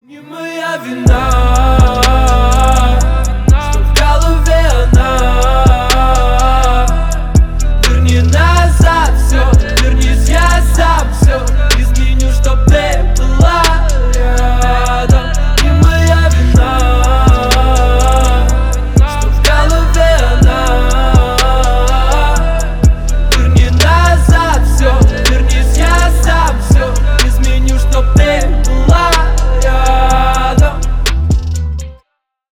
Поп Музыка
спокойные # грустные